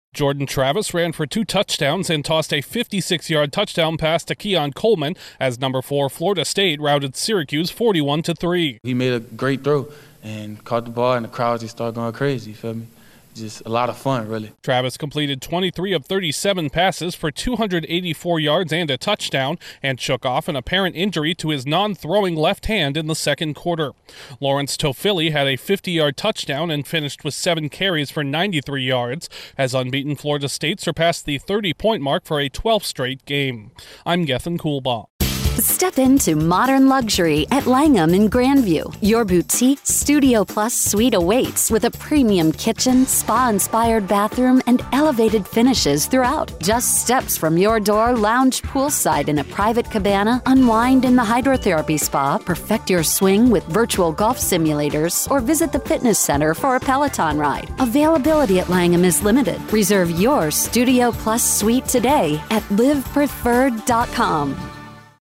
Florida State has another strong offensive showing. Correspondent